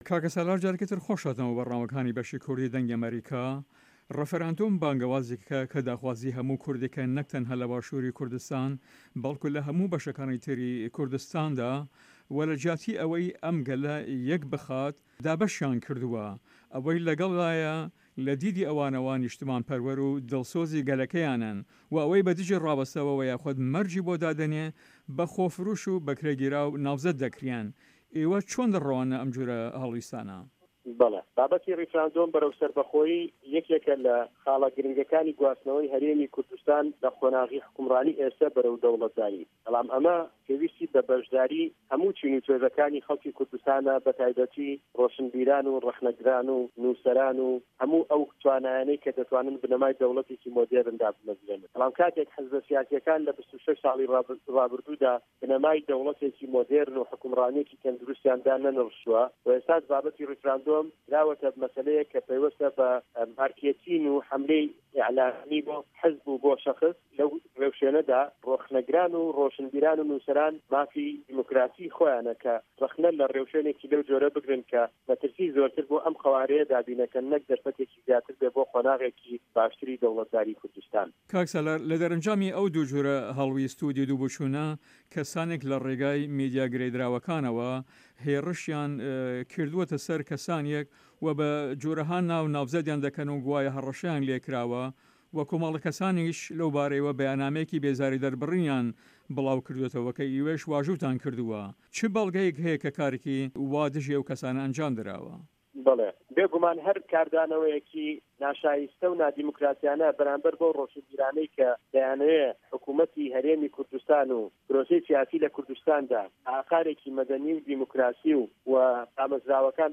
Interview with Salar Mahmoud